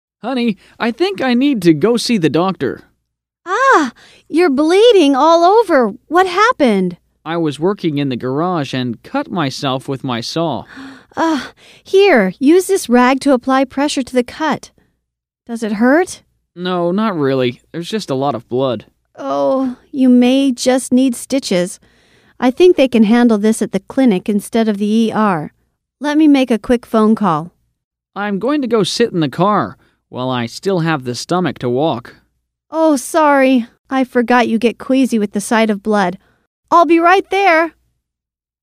英语情景对话